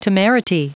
Prononciation du mot temerity en anglais (fichier audio)
Prononciation du mot : temerity